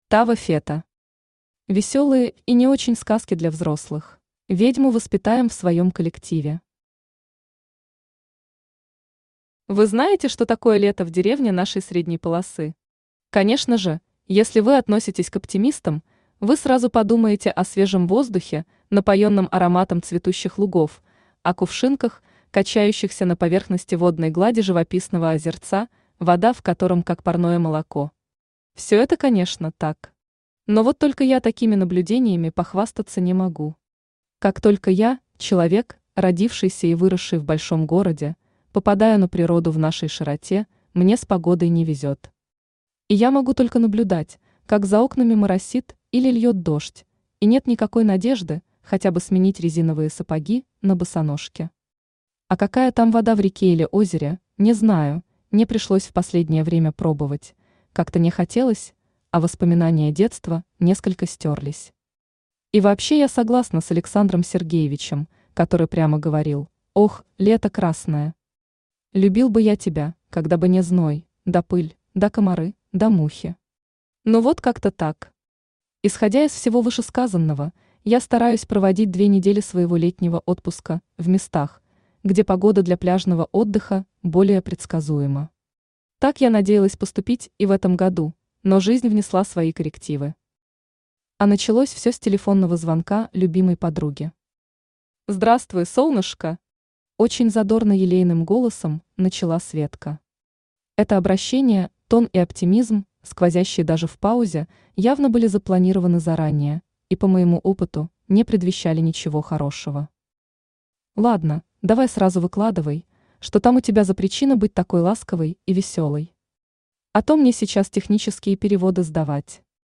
Aудиокнига Веселые и не очень сказки для взрослых Автор Тава Фето Читает аудиокнигу Авточтец ЛитРес.